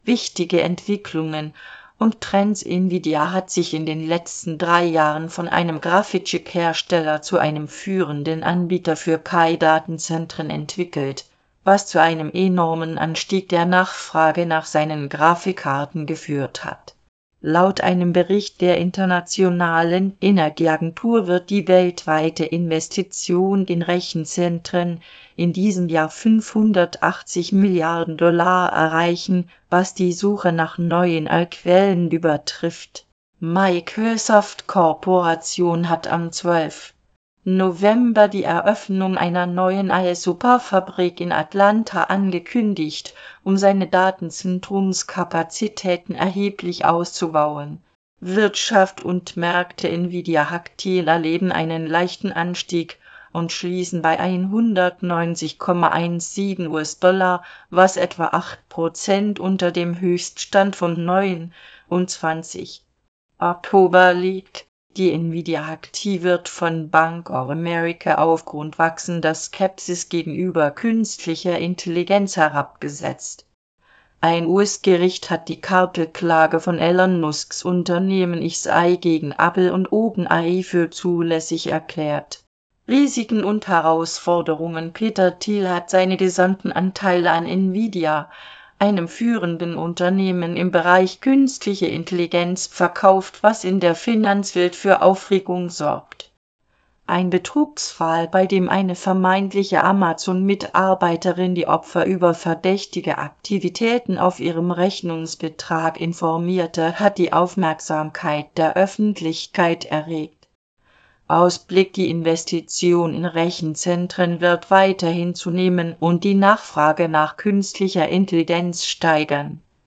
Vorlesen (MP3)